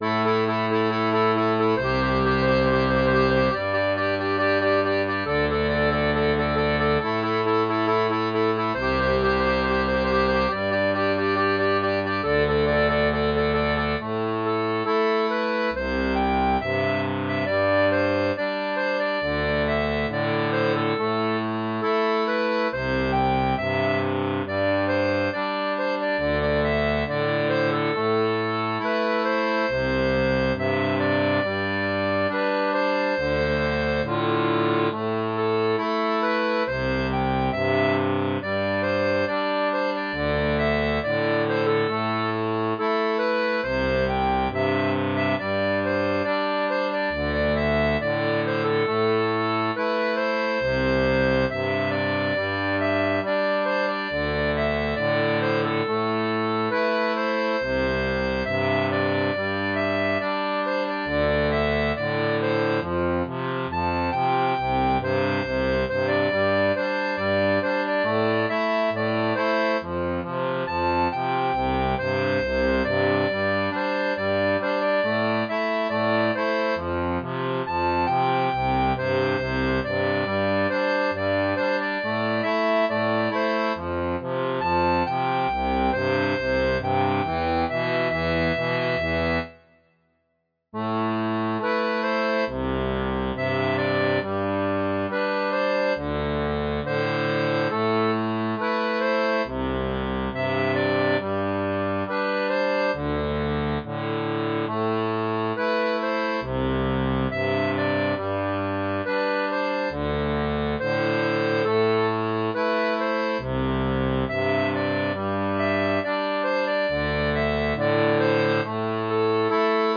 • La tablature transposée en D pour diato 2 rangs
Pop-Rock